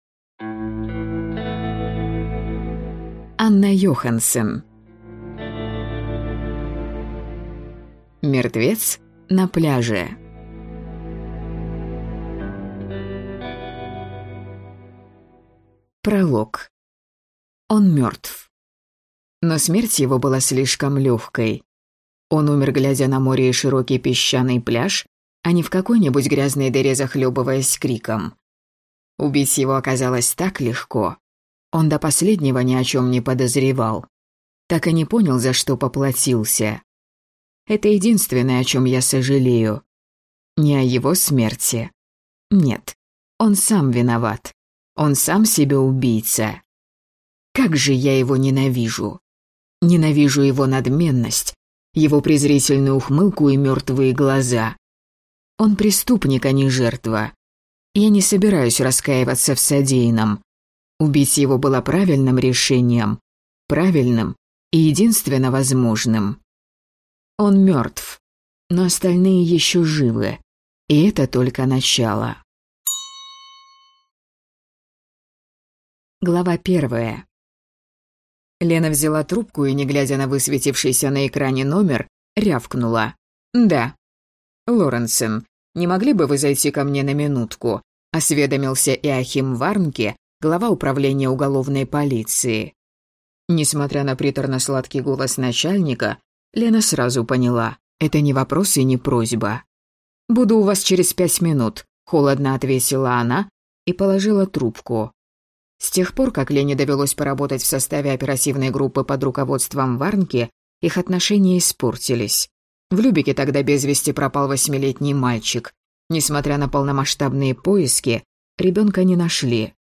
Аудиокнига Мертвец на пляже | Библиотека аудиокниг